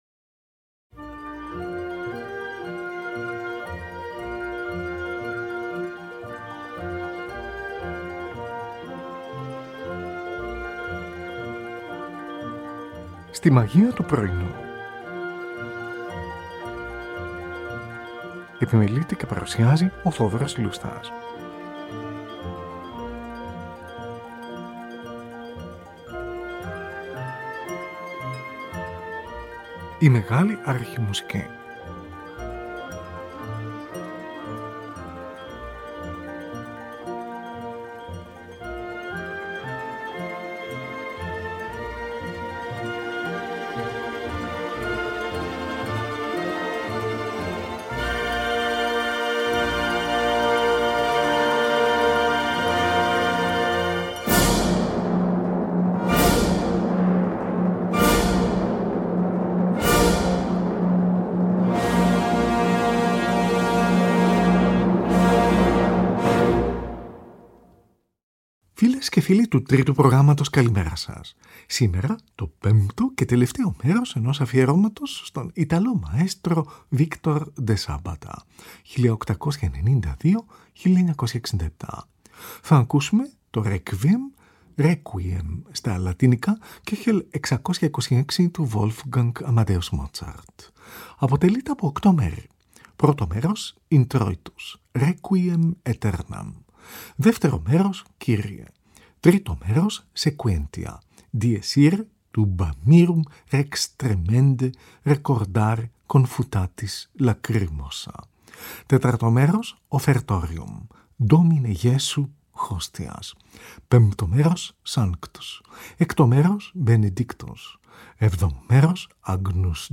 υψίφωνος
mezzo-soprano
τενόρος
βαθύφωνος
Τη Χορωδία και την Ορχήστρα της Iταλικής Ραδιοφωνίας διευθύνει ο Victor de Sabata , από ηχογράφηση στη Ρώμη